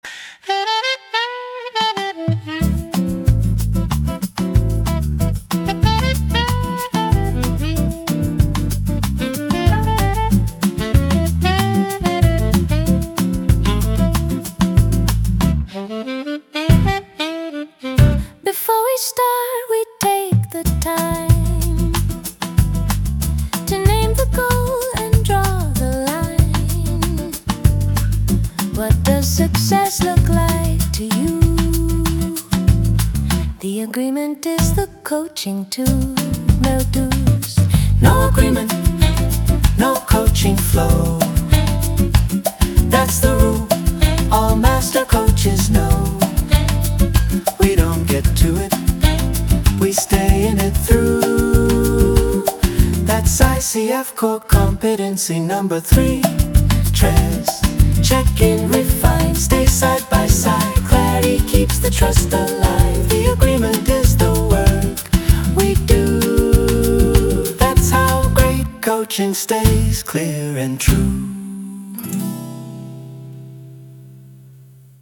We started playing with different musical styles, and with a little help from AI, we’ve been able to shape the music in a way that feels creative, light, and memorable.
It has a Brazilian Bossa Nova vibe, and the whole idea behind it is simple: